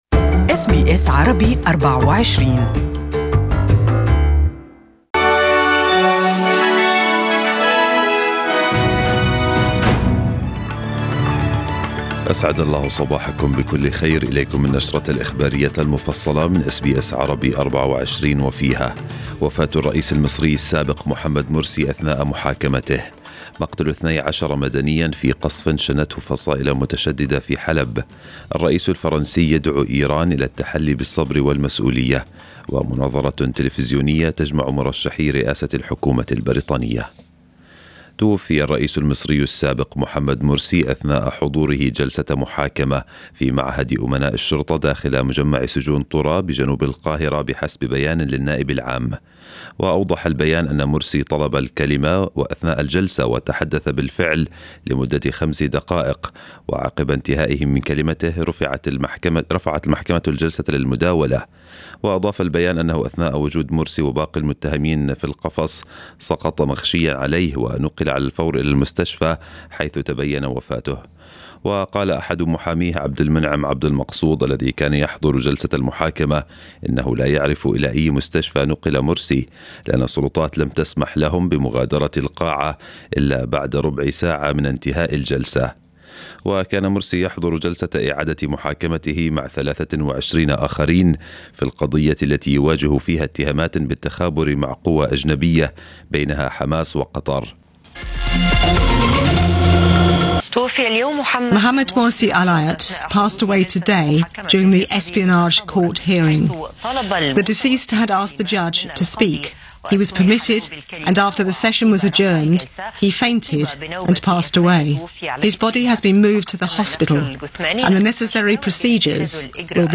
أخبار الصباح: وفاة الرئيس المصري السابق محمد مرسي أثناء محاكمته
A24 Morning N ews Bulletin Source: Getty